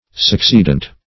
Succeedant \Suc*ceed"ant\